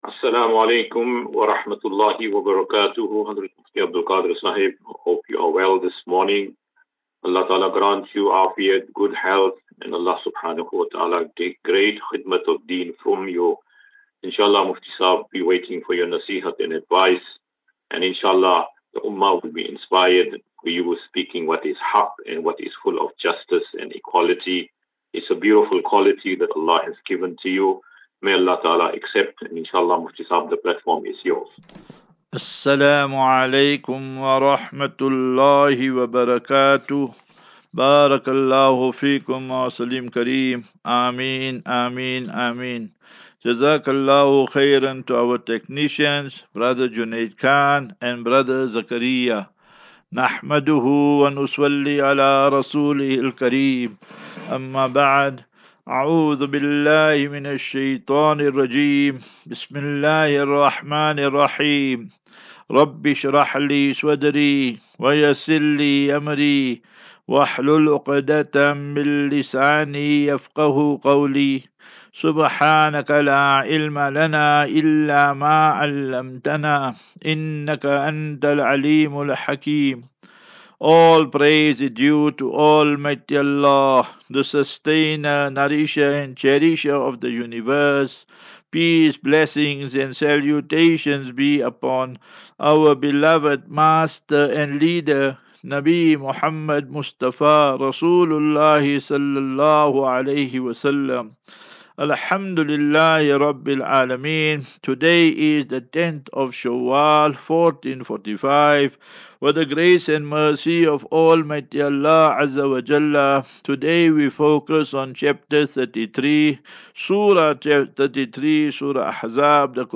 20 Apr 20 April 2024. Assafinatu - Illal - Jannah. QnA